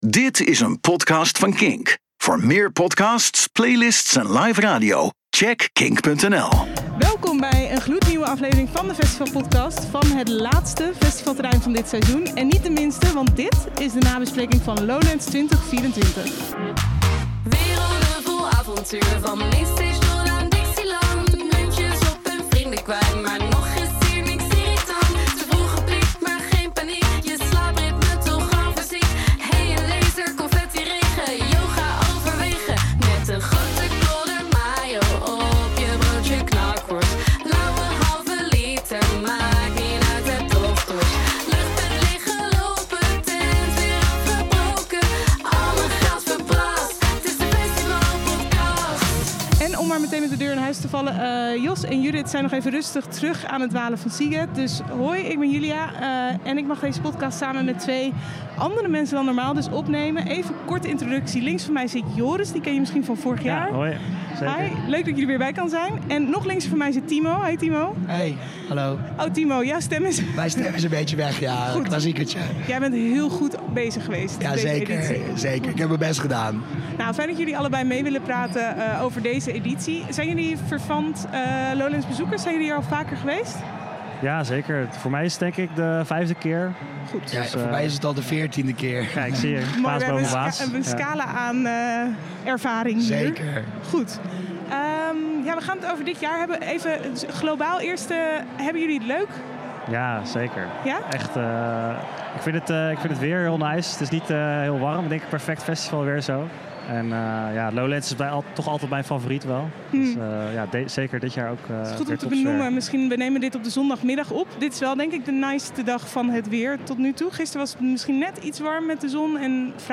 Nieuwe aflevering van de festivalpodcast, ditmaal vanaf het Lowlandsterrein!